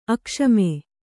♪ akṣame